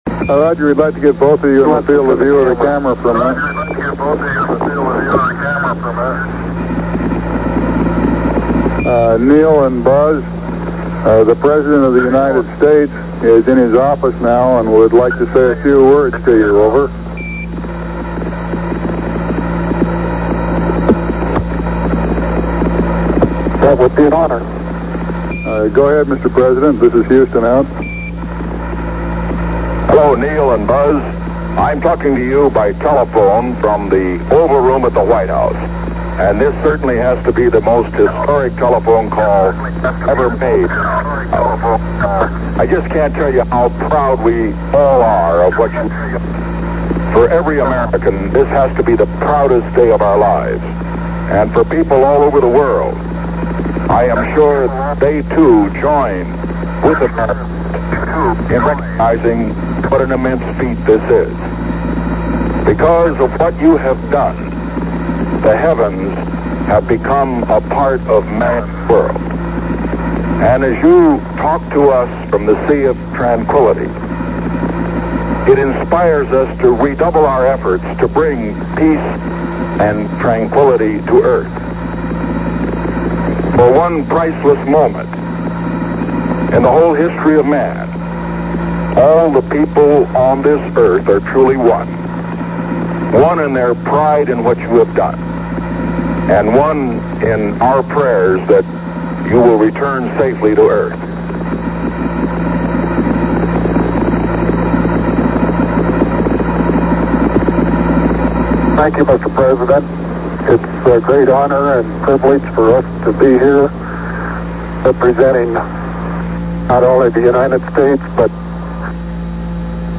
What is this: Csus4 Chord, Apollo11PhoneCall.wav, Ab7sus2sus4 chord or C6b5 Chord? Apollo11PhoneCall.wav